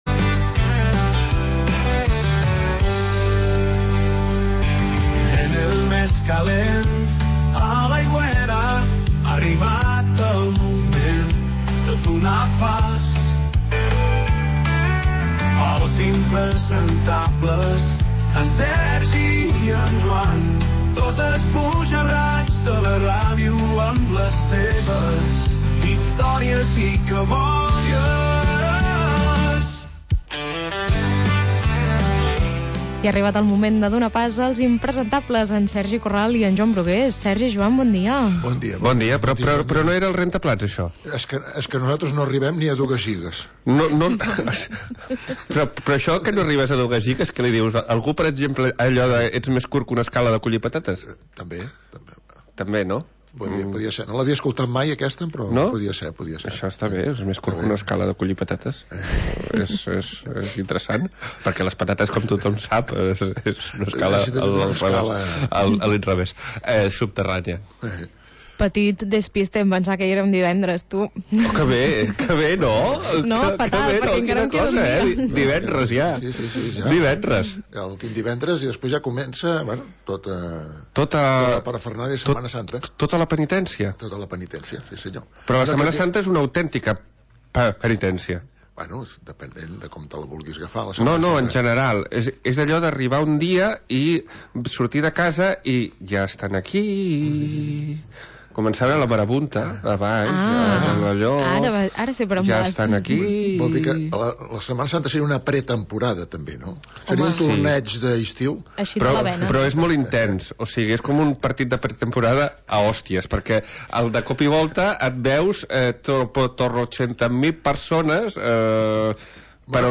Històries i cabòries de dos esbojarrats de la ràdio.